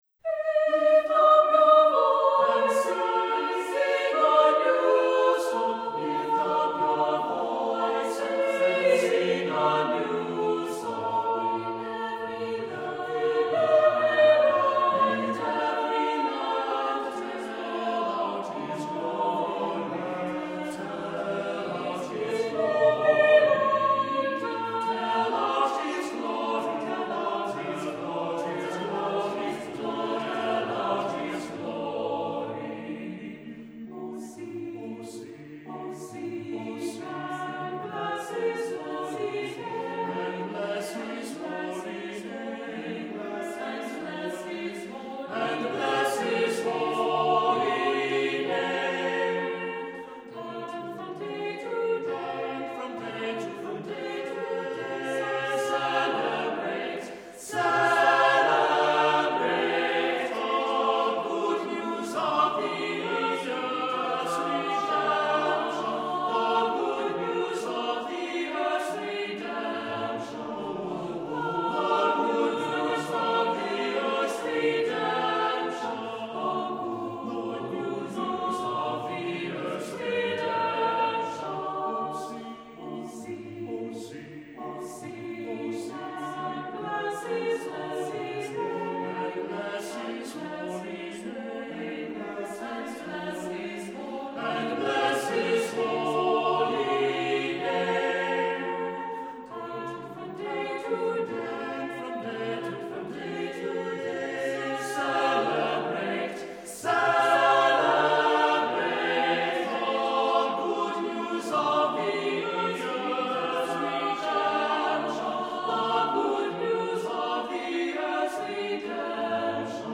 Accompaniment:      A Cappella
Music Category:      Early Music